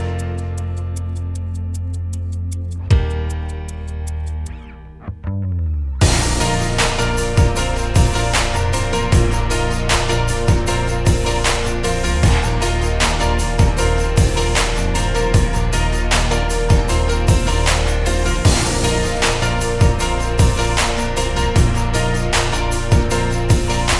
no Backing Vocals Pop (2010s) 3:47 Buy £1.50